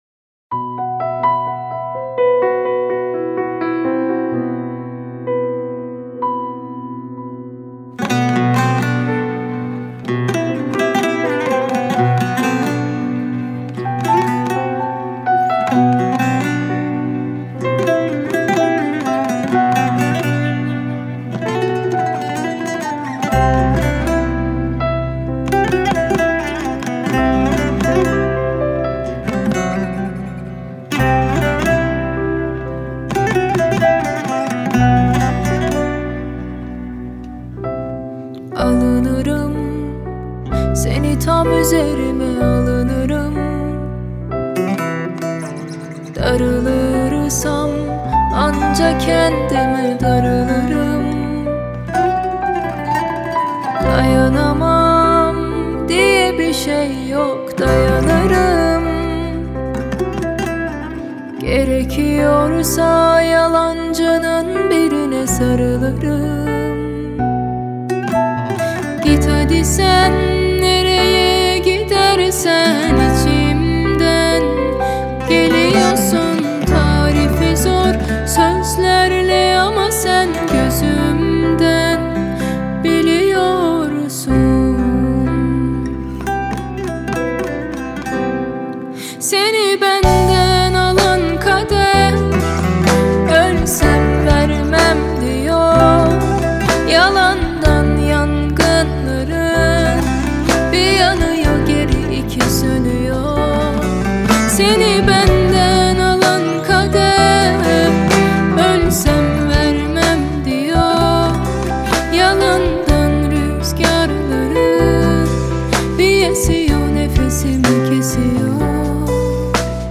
آهنگ ترکیه ای آهنگ غمگین ترکیه ای